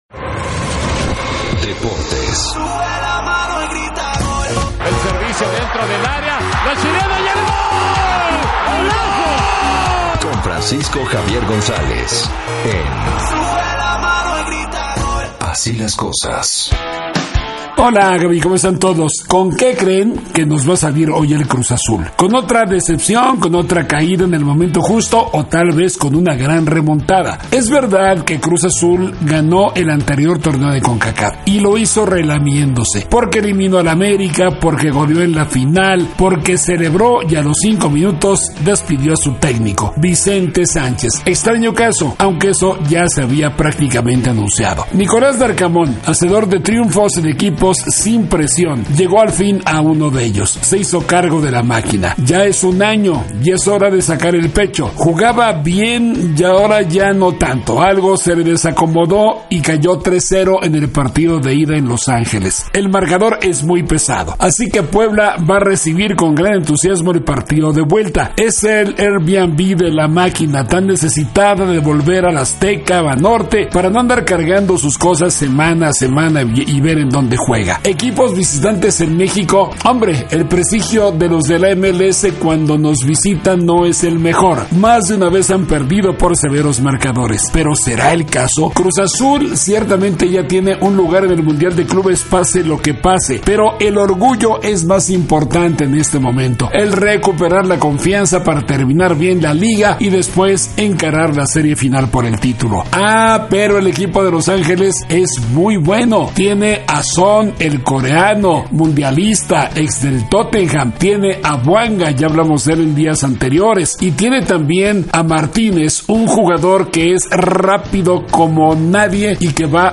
periodista y comentarista deportivo